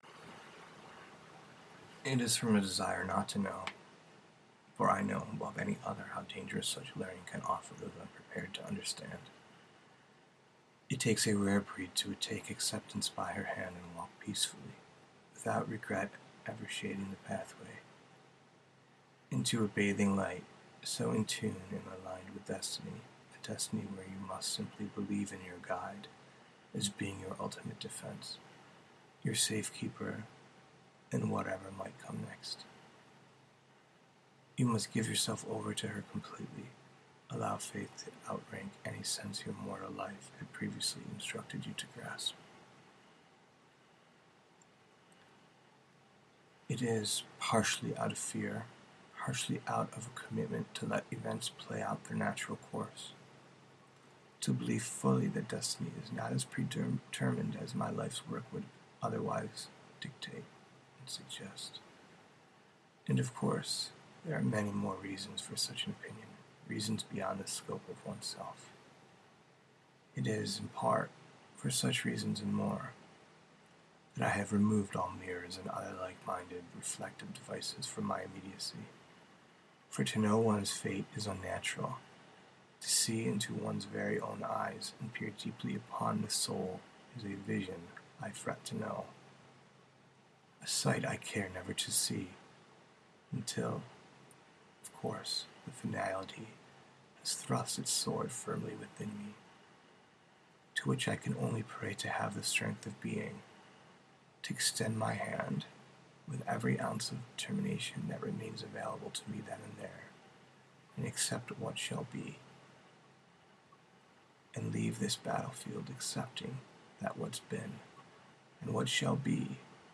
Reading, Fate, Premonitions, Sight, metaphor, prose poetry, poetry, recording